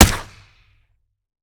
weap_beta_sup_fire_plr_01.ogg